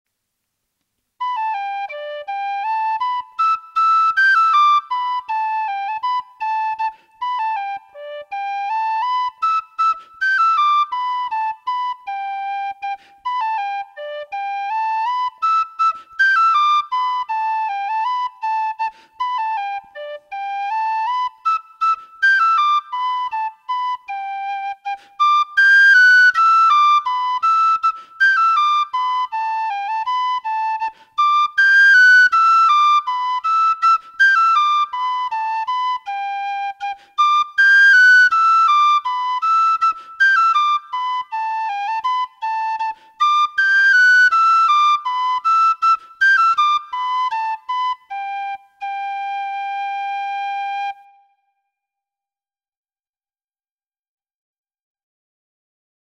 Traditional Irish Music - learning resources
Tune book recording